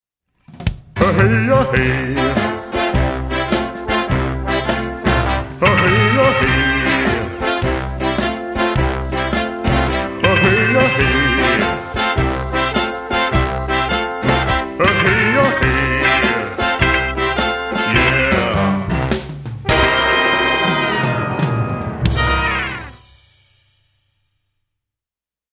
A Rockin’ Holiday Fantasy For Young Voices